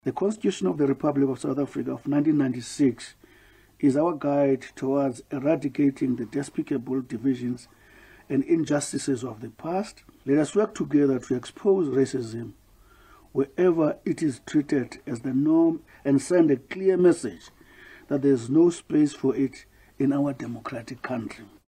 Minister in The Presidency Mondli Gungubele addressed a post-Cabinet briefing this week and said Cabinet strongly condemned the recent incidents of racism reported at two Western Cape educational institutions.